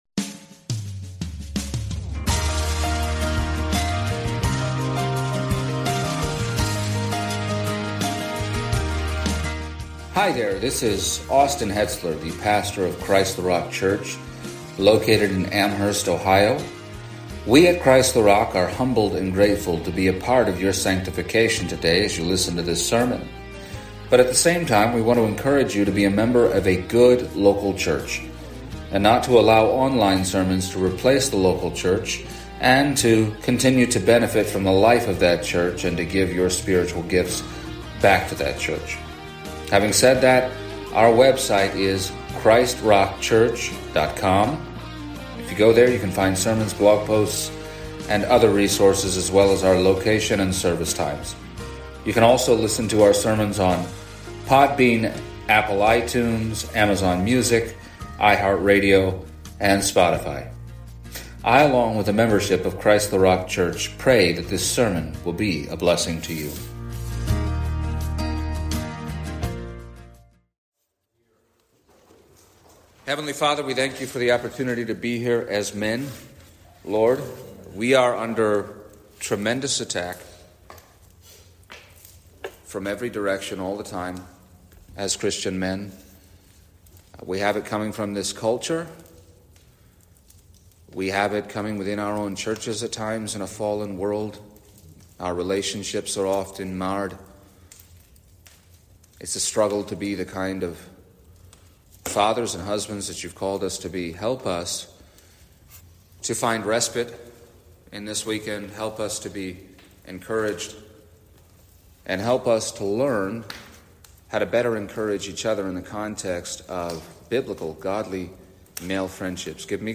The first of three messages given at the inaugural CtRC Men’s Summit.